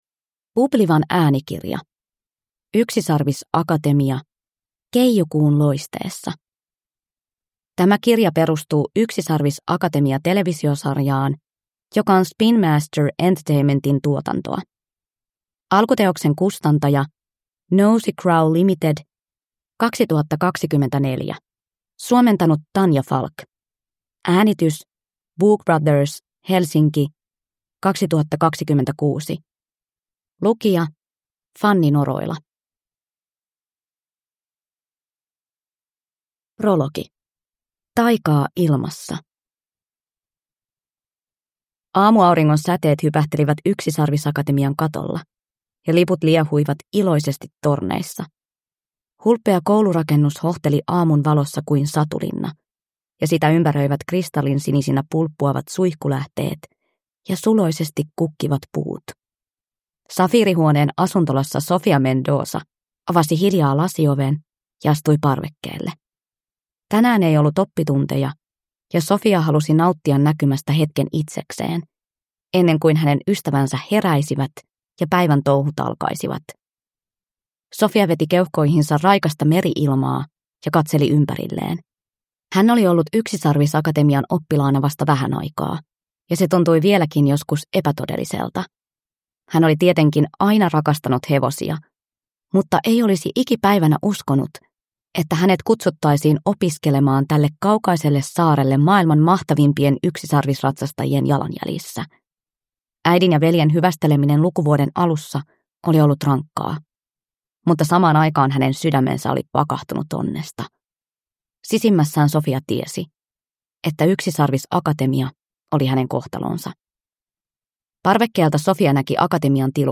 Yksisarvisakatemia - Keijukuun loisteessa – Ljudbok